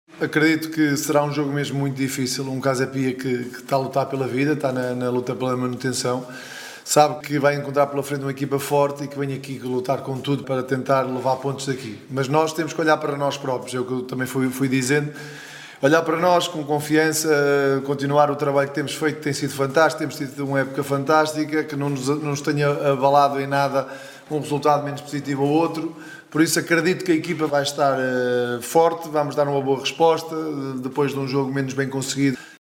César Peixoto, treinador do conjunto barcelense, diz que a equipa vai “dar uma boa resposta”.